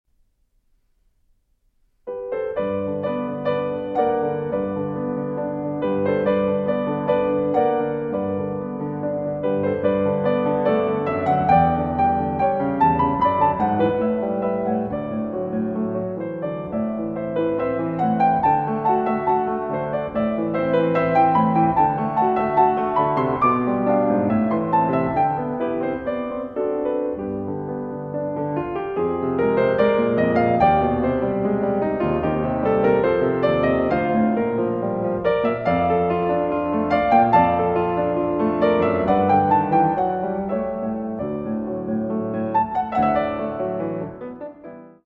Moderato con moto (4:29)